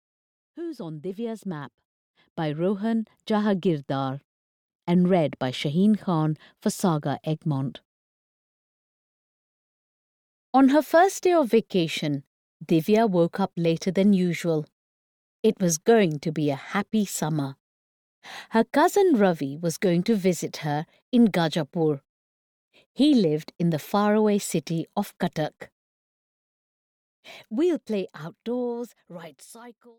Who's on Divya's Map (EN) audiokniha
Ukázka z knihy